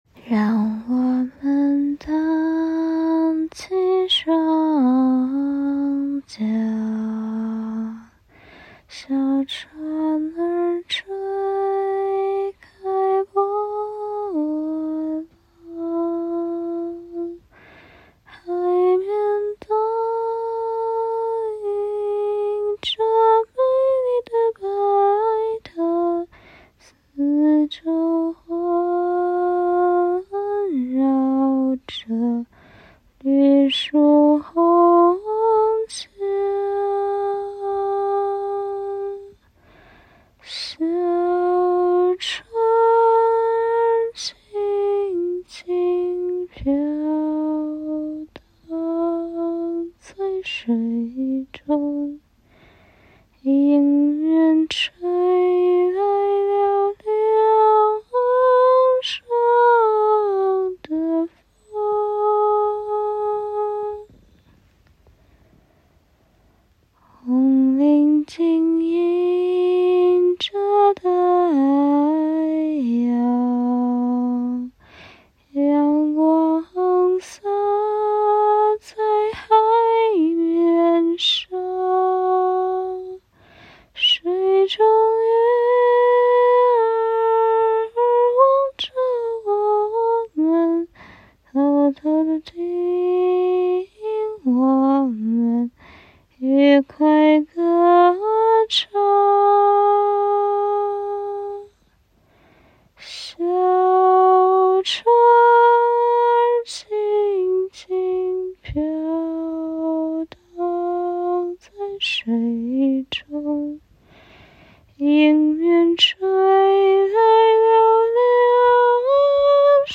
一点催眠曲:baba_spike: